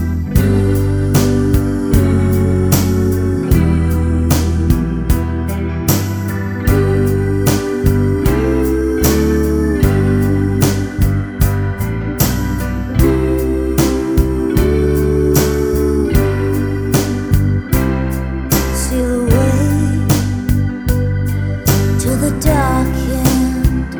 no Backing Vocals Soundtracks 2:35 Buy £1.50